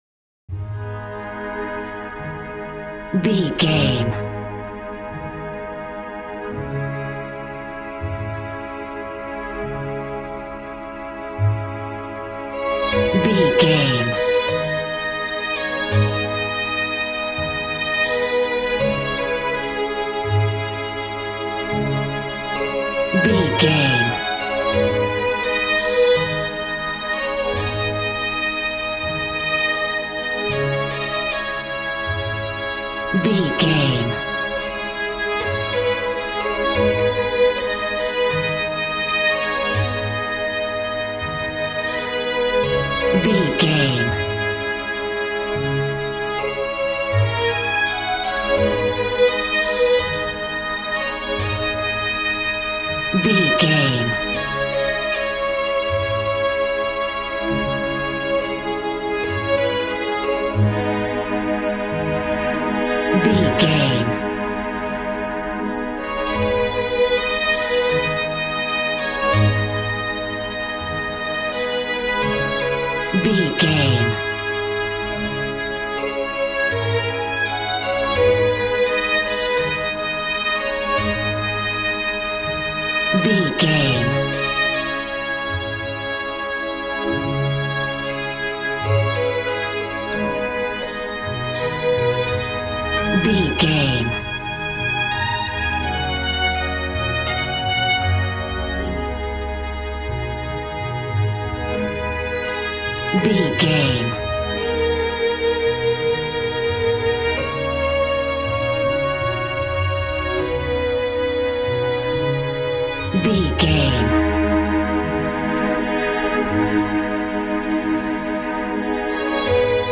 Aeolian/Minor
A♭
Fast
joyful
conga
80s